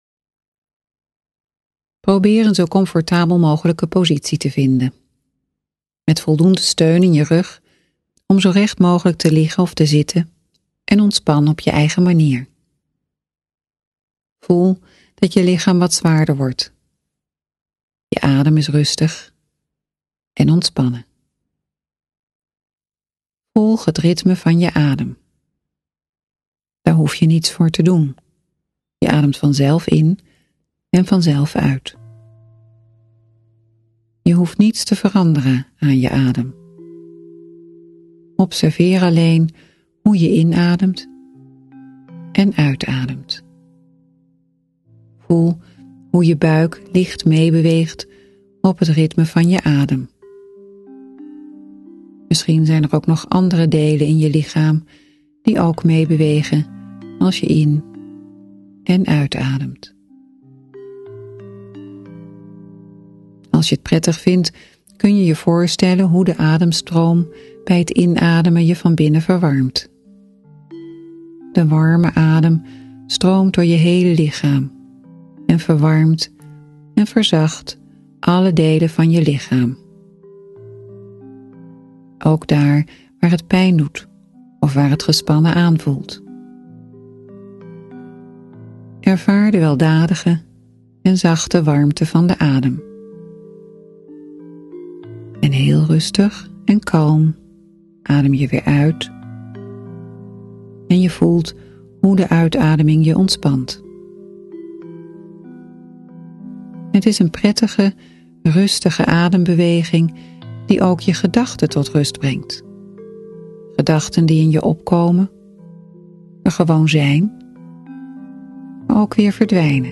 De oefeningen worden ondersteund met speciaal hiervoor gecomponeerde muziek